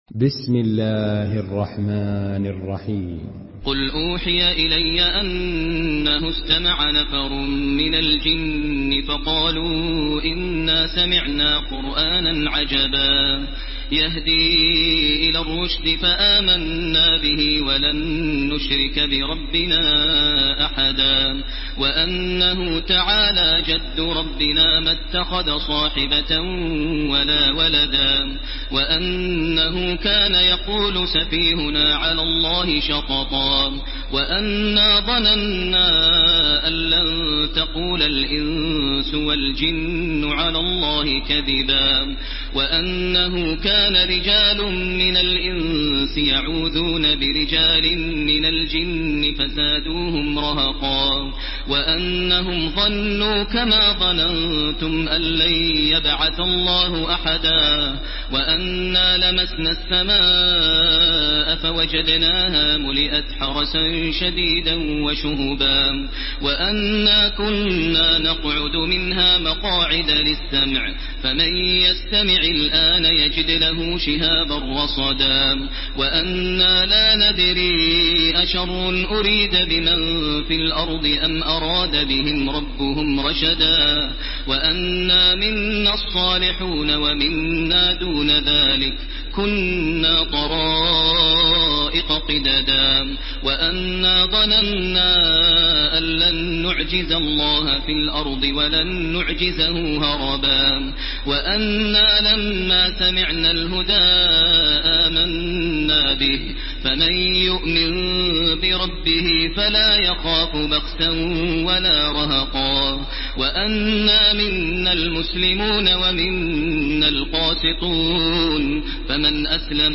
Surah Al-Jinn MP3 by Makkah Taraweeh 1430 in Hafs An Asim narration.
Murattal